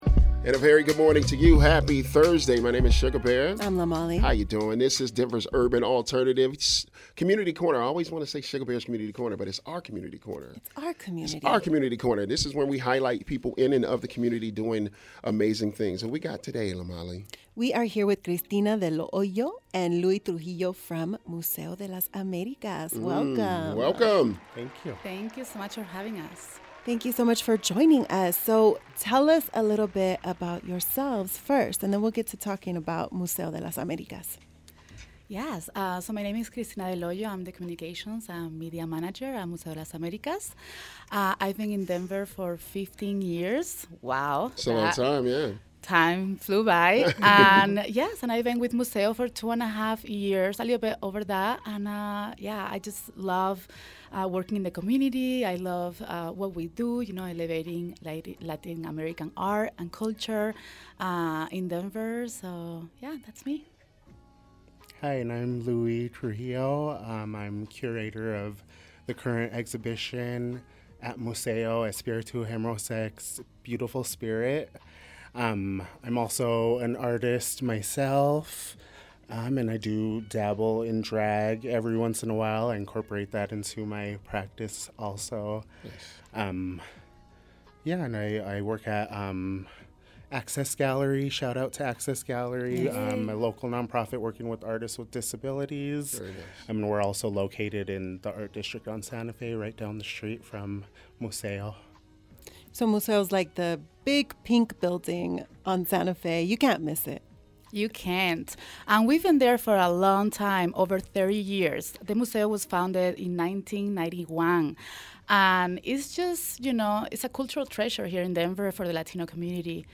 This wasn't just any conversation; it was a lively exchange brimming with passion for celebrating Latin American art and heritage.